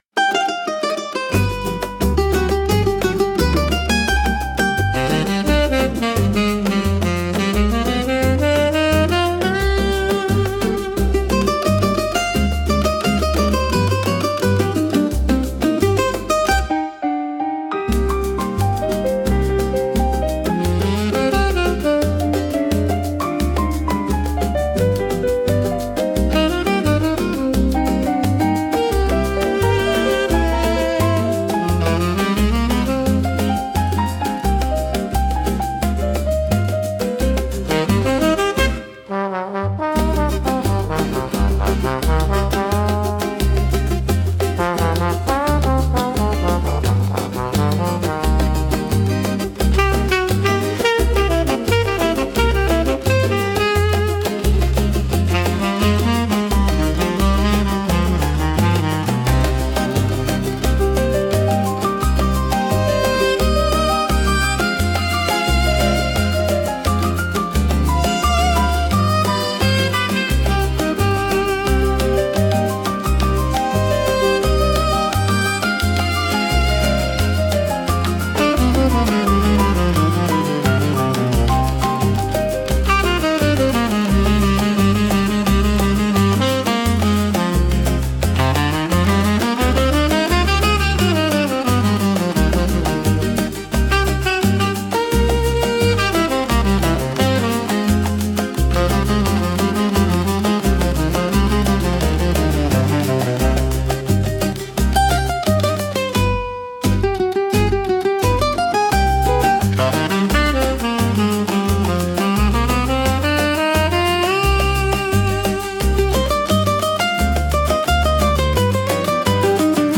instrumental 10